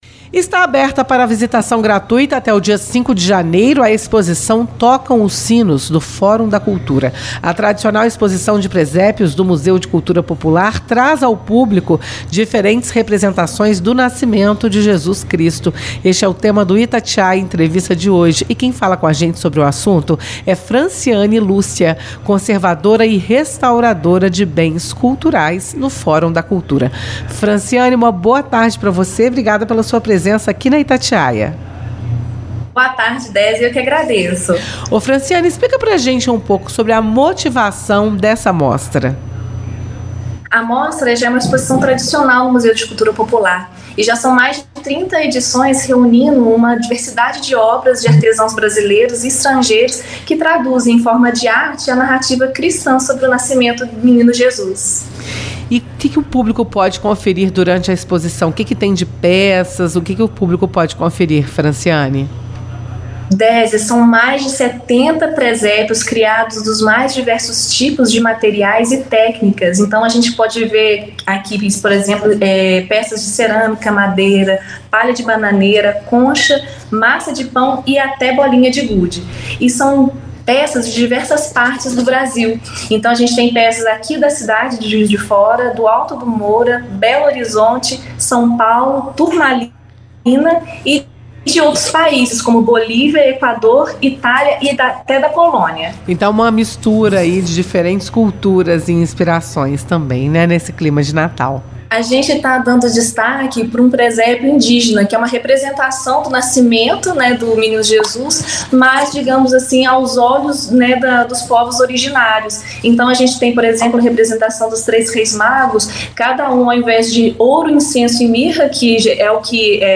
Itatiaia-Entrevista-Exposicao-Tocam-os-Sinos.mp3